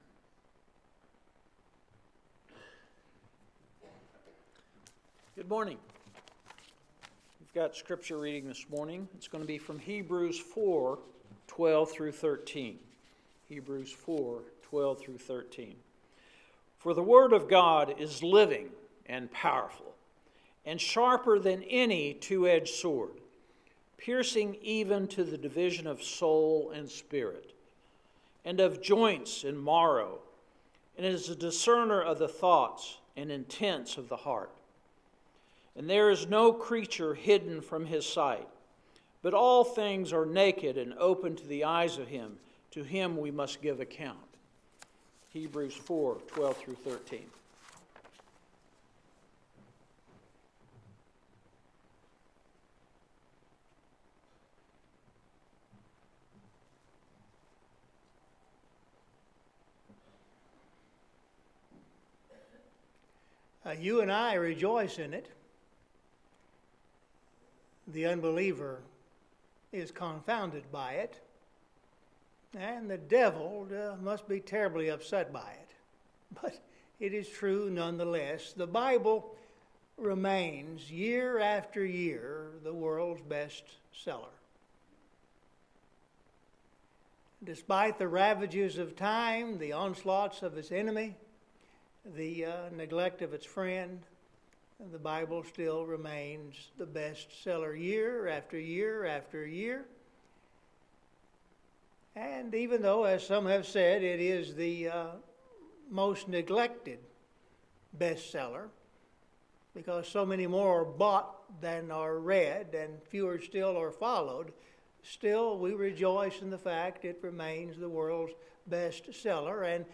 Scripture Reading – Hebrews 4:12-13